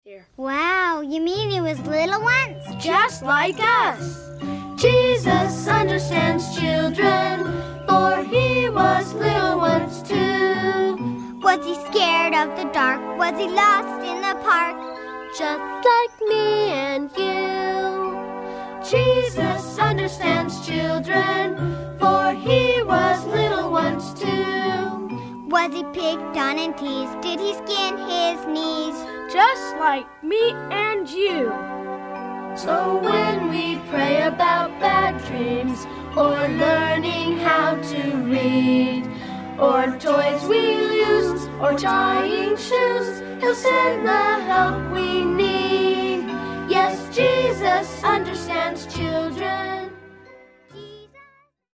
Selected Song Samples